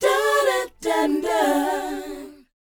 DOWOP A FU.wav